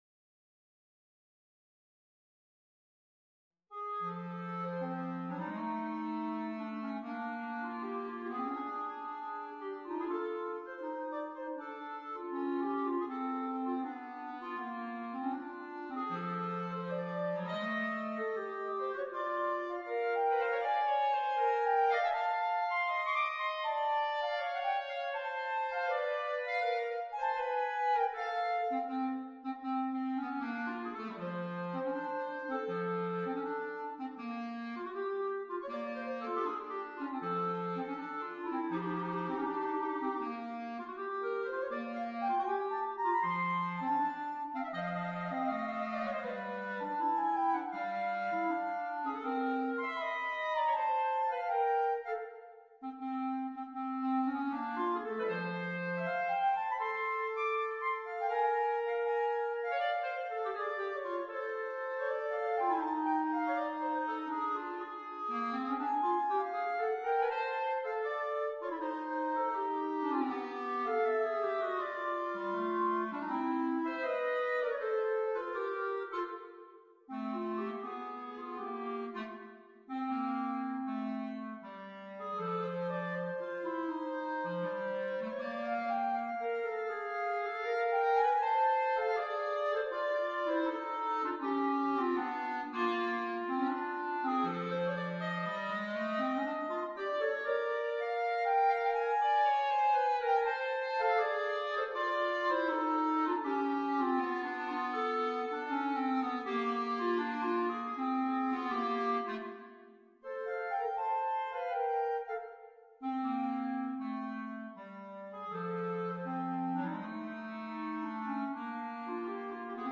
per due clarinetti